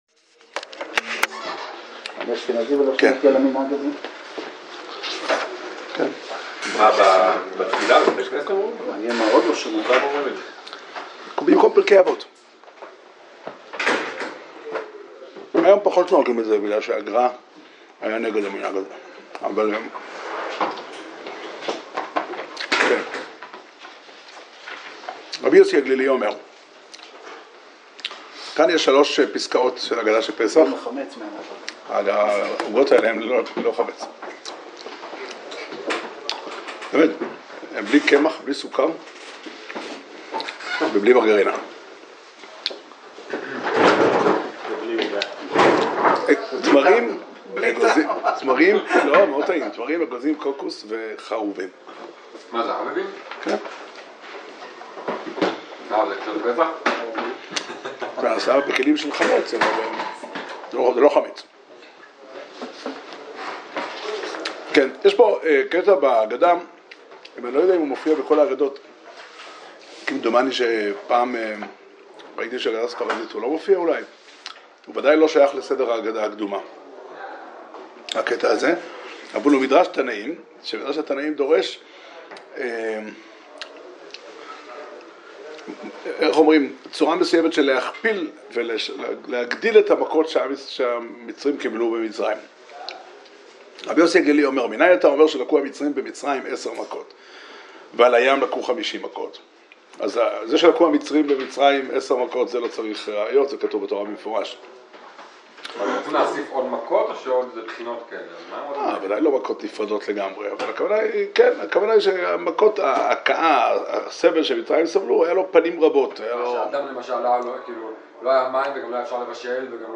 שיעור שנמסר בבית המדרש 'פתחי עולם' בתאריך י"ח אדר ב' תשע"ט